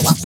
13 SCRATCH 1.wav